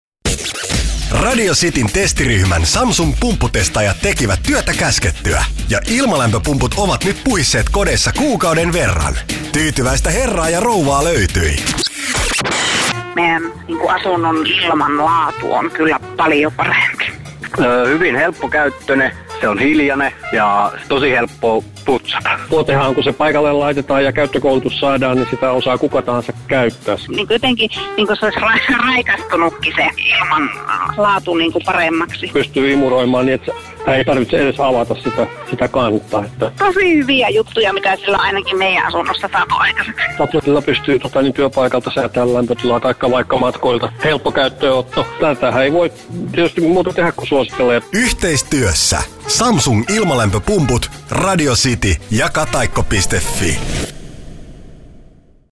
Kuuntele, mitä mieltä testaajat ovat Samsung Exlusive ilmalämpöpumpusta.